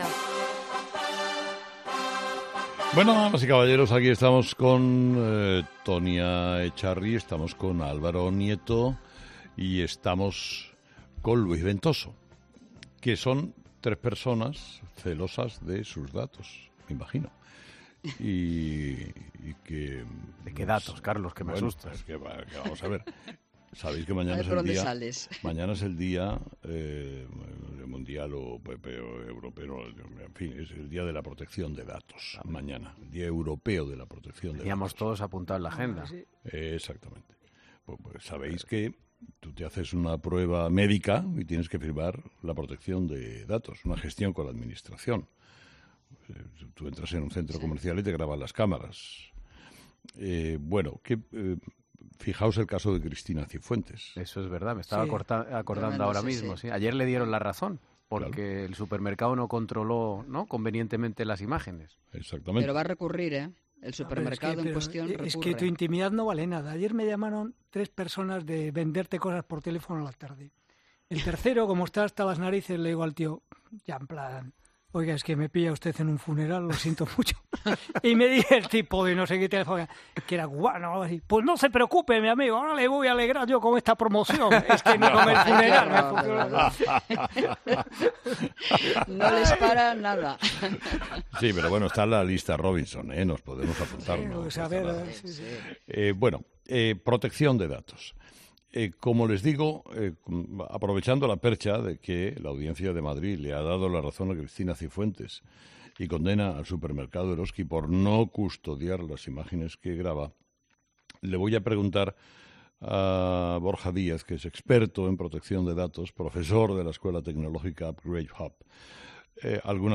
Un experto en protección de datos analiza cómo debe gestionar las imágenes una tienda que graba a sus clientes